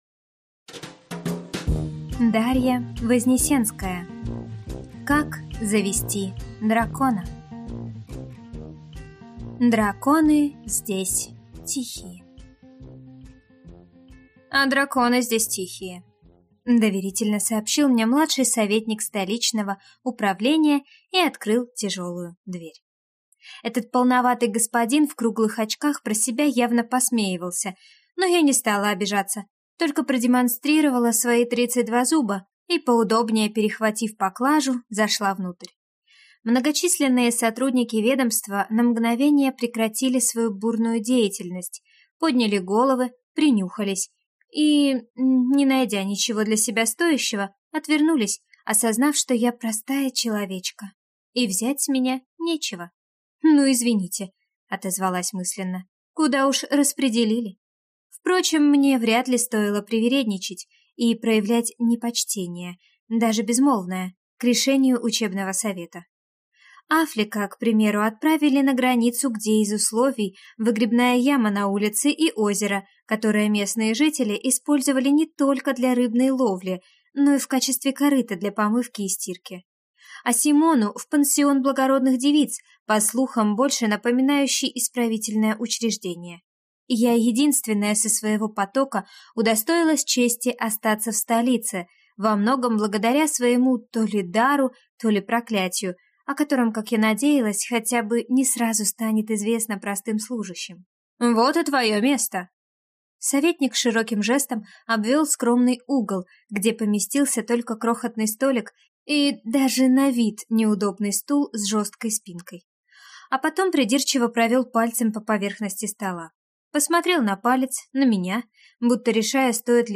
Аудиокнига Как завести дракона | Библиотека аудиокниг